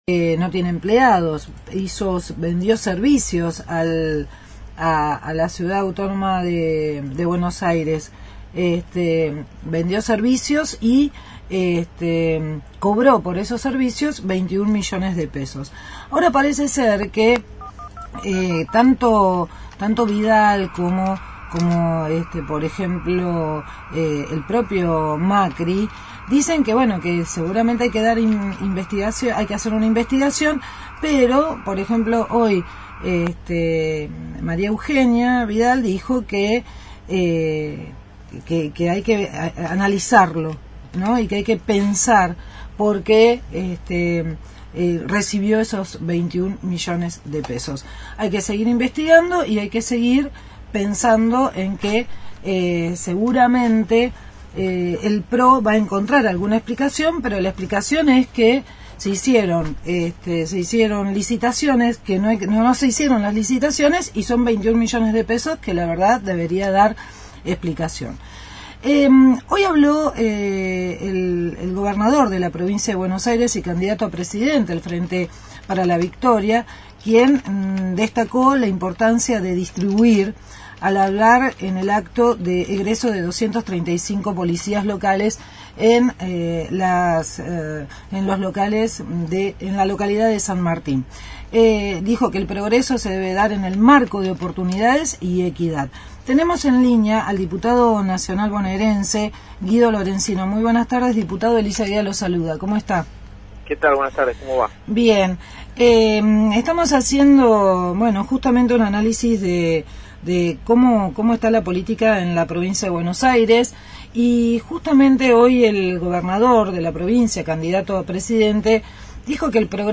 Entrevista al diputado bonaerense del Frente para la Victoria, Guido Lorenzino.